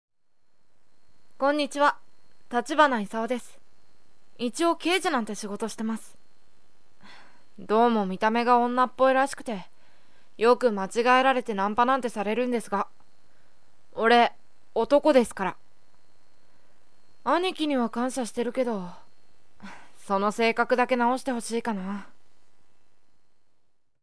かなりの女顔で、声もボーイソプラノ系と、非常に高く、
自己紹介